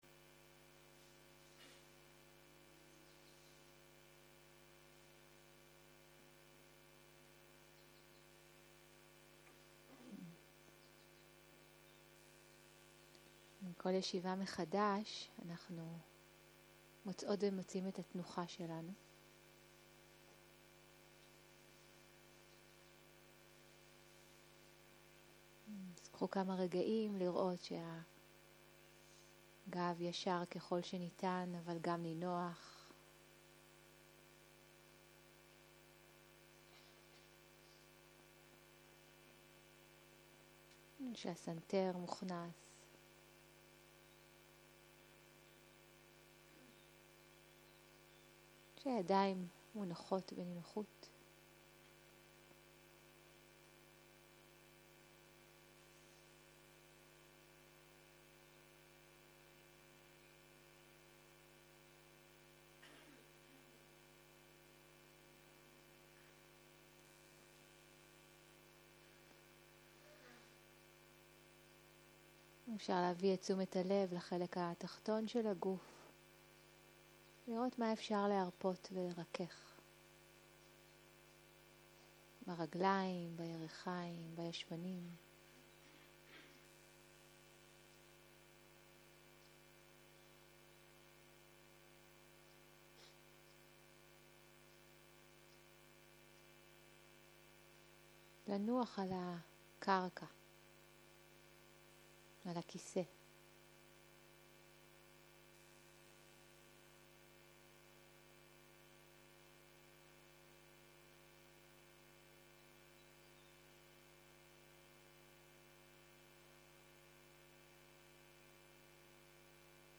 יום 3 - צהריים - מדיטציה מונחית - הקלטה 5